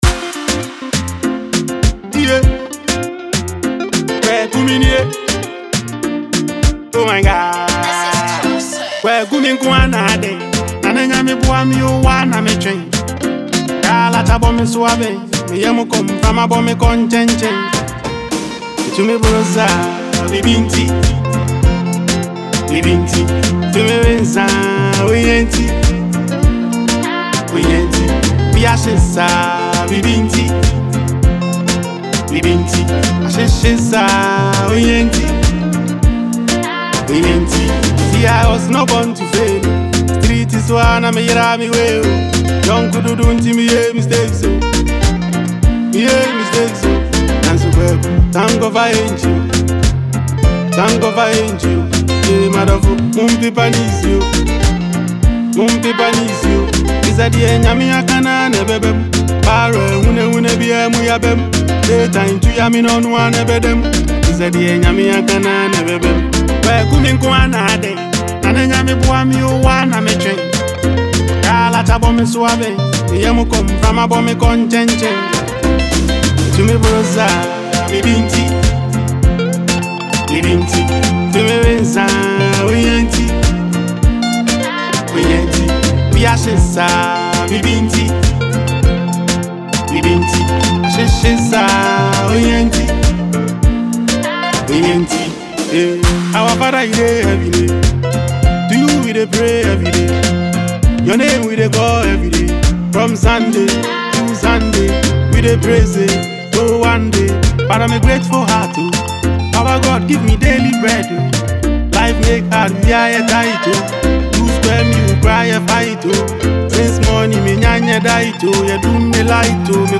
highlife track